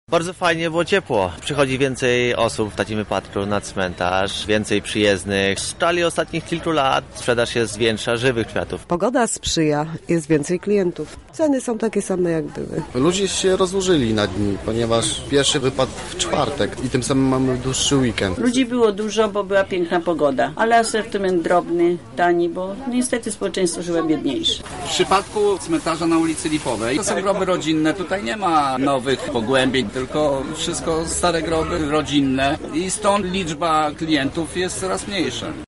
W rozmowie z naszym reporterem przyznają jednak, że to głównie za sprawą sprzyjającej pogody.